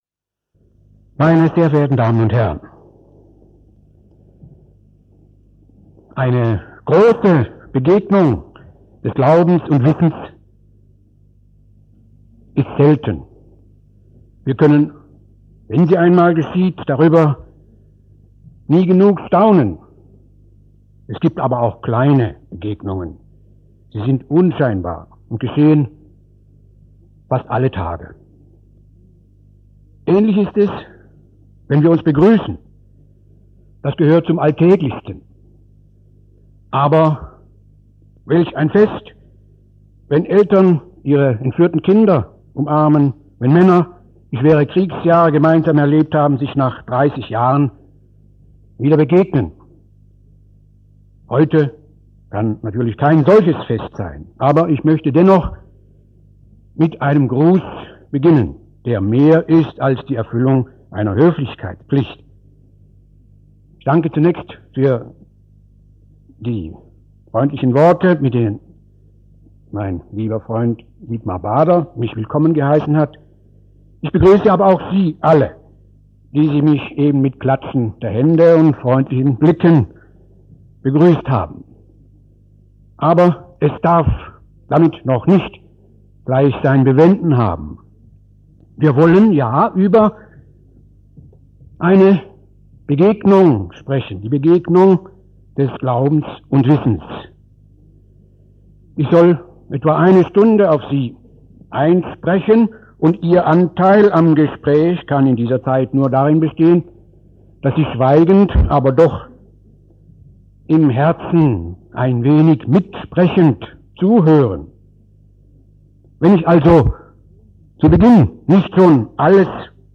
Zur Begegnung des Glaubens und Wissens in der Zeit Alberts des Großen und in unserer Zeit - Rede des Monats - Religion und Theologie - Religion und Theologie - Kategorien - Videoportal Universität Freiburg